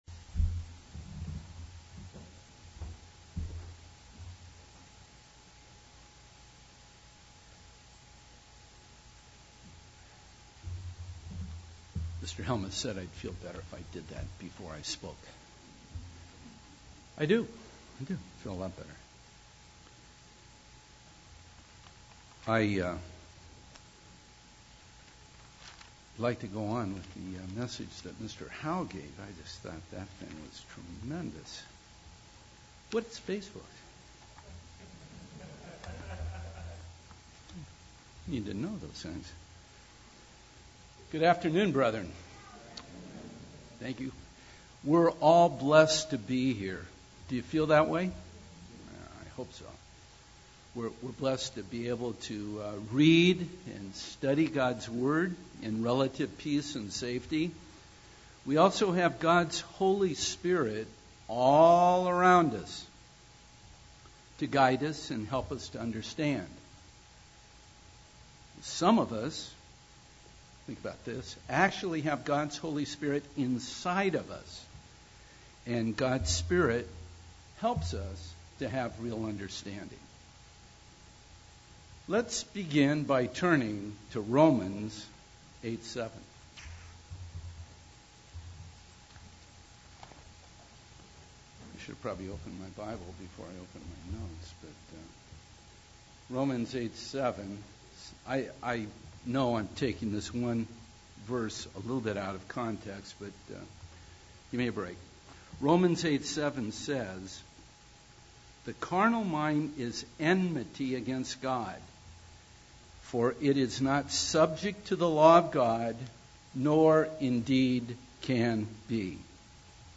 Print Gnosticism's Two Main, Different Aspects UCG Sermon Studying the bible?